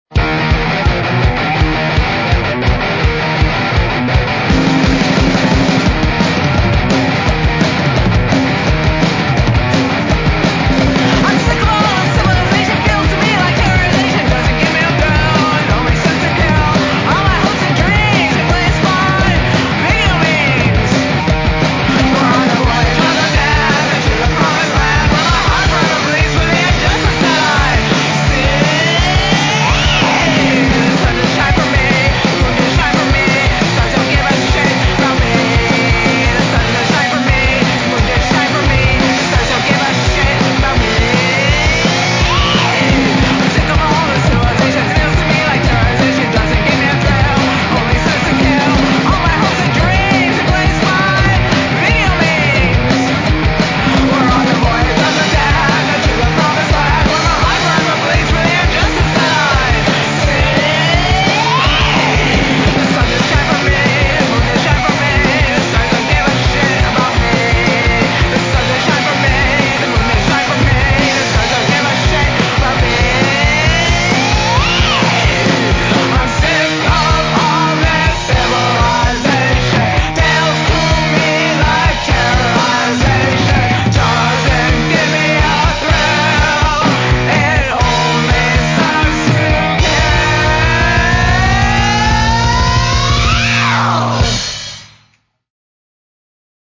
Guitar and Lead Vocal
Drums, Percussion, and Backing Vocal